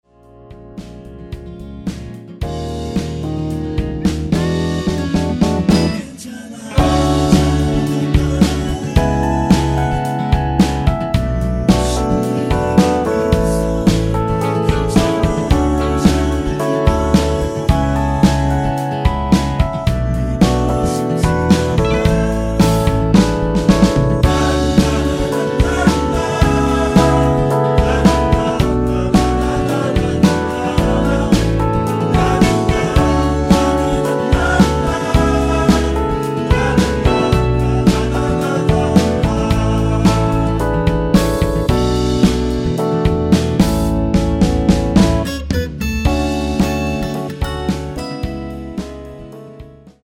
(-1) 내린 코러스 포함된 MR 입니다.(미리듣기 참조)
Ab
◈ 곡명 옆 (-1)은 반음 내림, (+1)은 반음 올림 입니다.
앞부분30초, 뒷부분30초씩 편집해서 올려 드리고 있습니다.